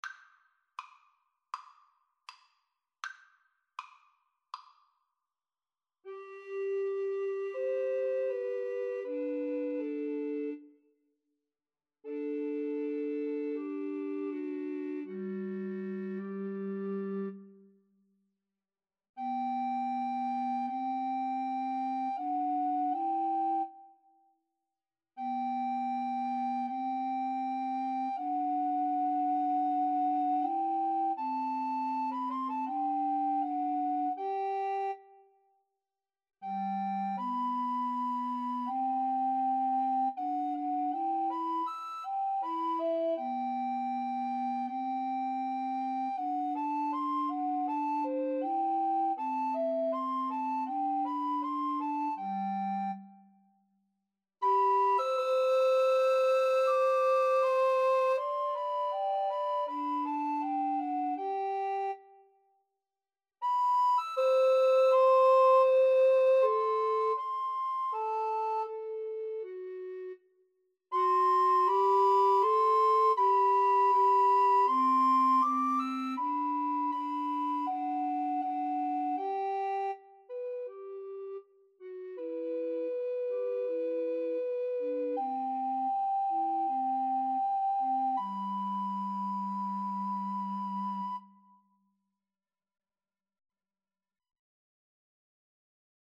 G major (Sounding Pitch) (View more G major Music for Recorder Trio )
Andante